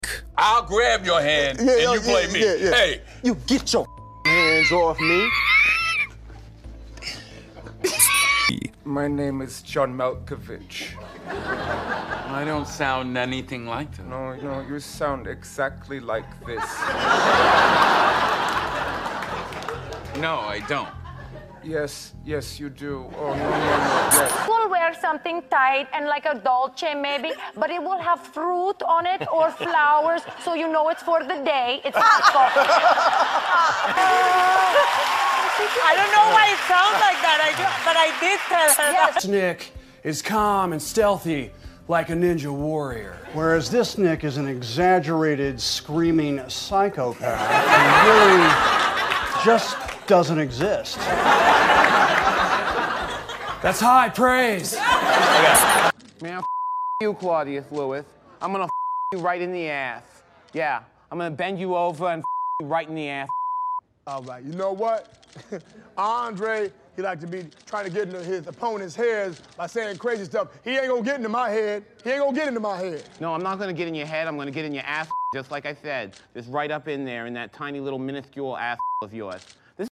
Voice impressions of Celebrities